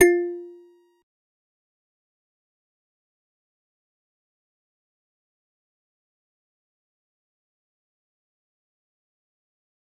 G_Musicbox-F4-pp.wav